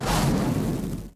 foundry-fire-whoosh-1.ogg